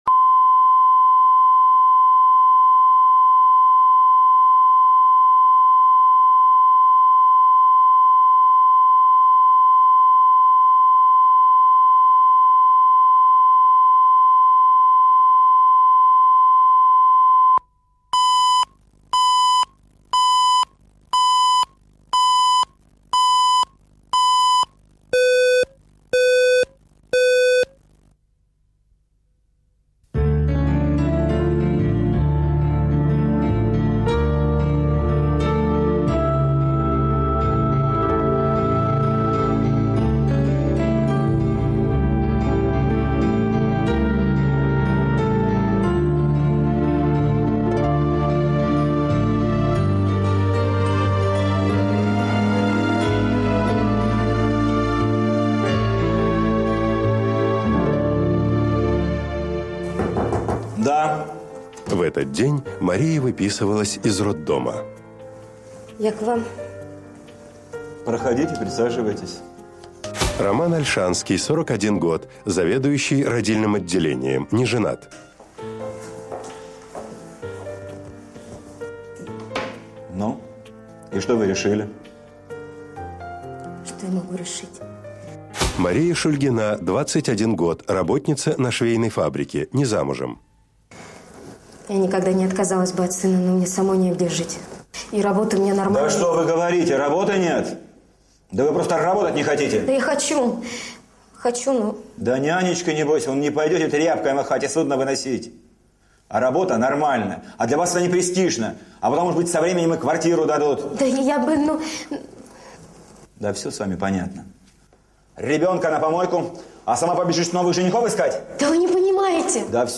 Аудиокнига Дом для малютки | Библиотека аудиокниг